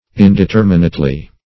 -- In`de*ter"mi*nate*ly adv.